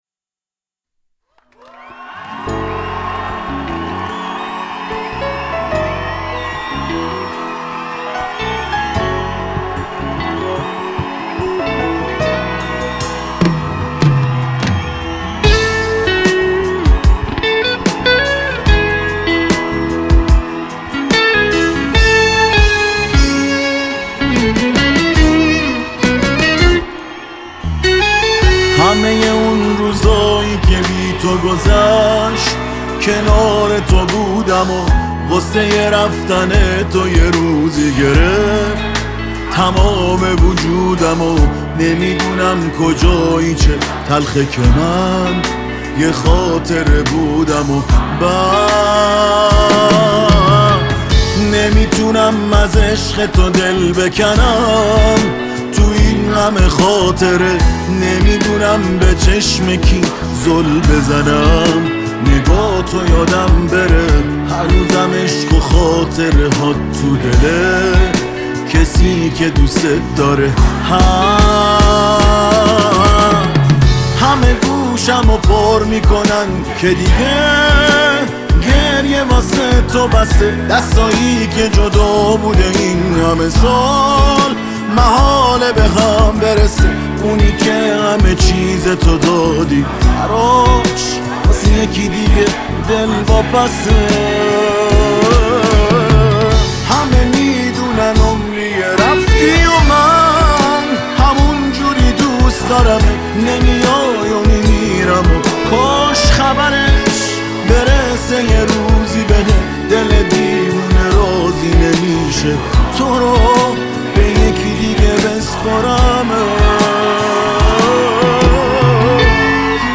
ورژن لایو کنسرت اضافه شد
دانلود ورژن کنسرت